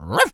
dog_small_bark_01.wav